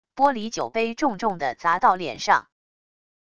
玻璃酒杯重重的砸到脸上wav音频